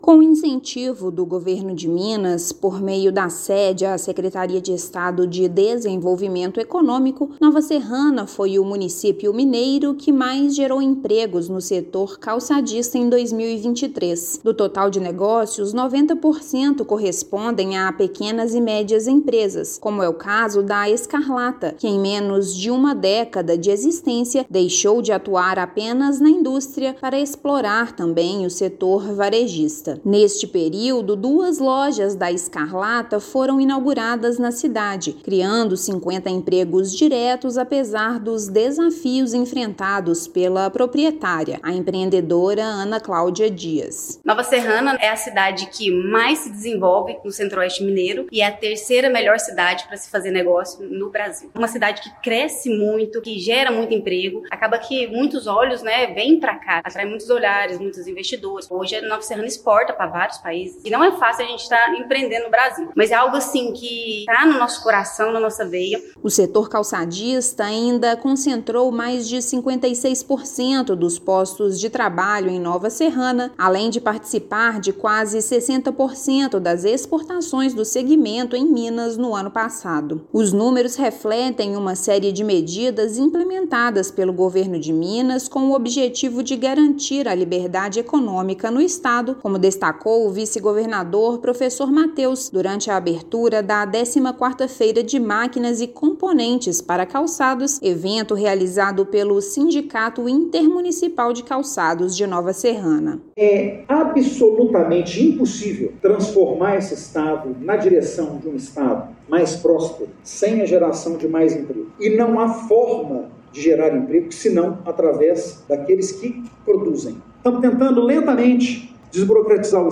Vice-governador participou da abertura da 14ª Febrac na cidade que produz, anualmente, mais de 100 milhões de pares de calçados. Ouça matéria de rádio.